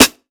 Sampled Snare.wav